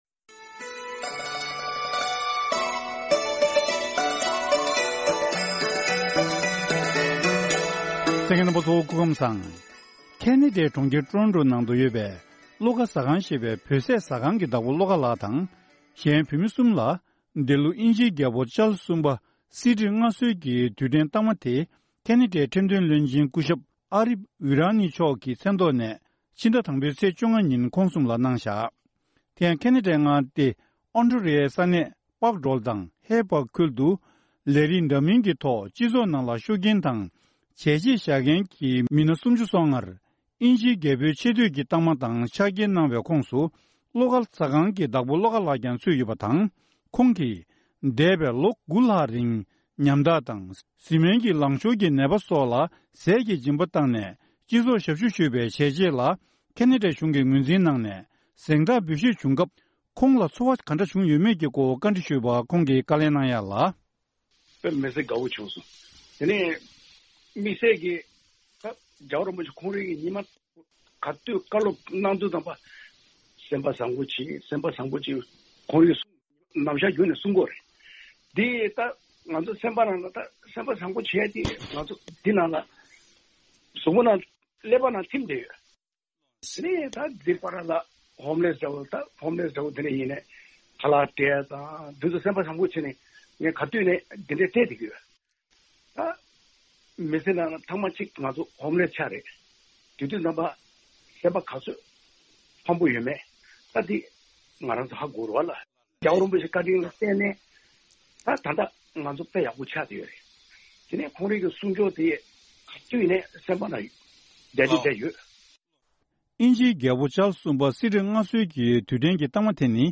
ཁོང་ལ་གནས་འདྲི་ཞུས་ ནས་ཕྱོགས་སྒྲིགས་ཞུས་པ་ཞིག་གསན་རོགས་གནང་།